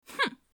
hmph Meme Sound Effect
Category: Reactions Soundboard
hmph.mp3